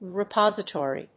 音節re・pos・i・to・ry 発音記号・読み方
/rɪpάzət`ɔːri(米国英語), ri:ˈpɑ:zʌˌtɔ:ri:(英国英語)/